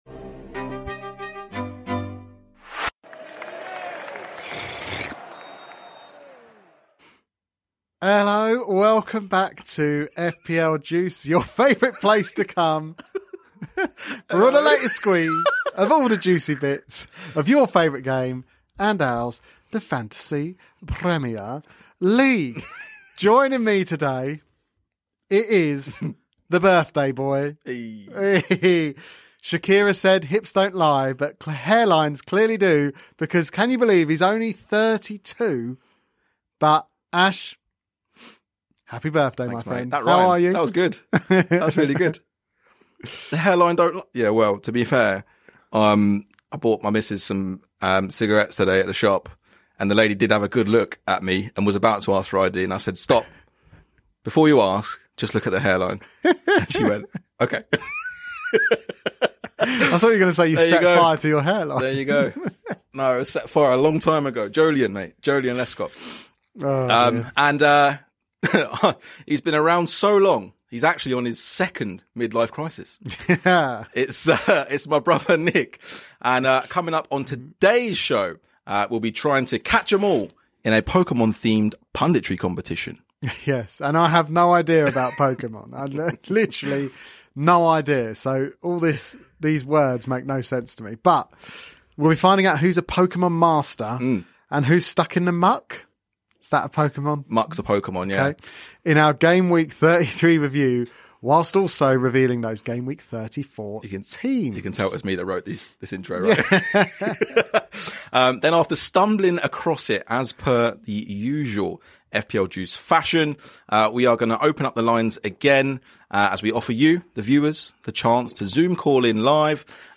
Oh and we open The JUiCE Bar once more for our viewers to Zoom-call into the show for a cheeky chat about all things topical!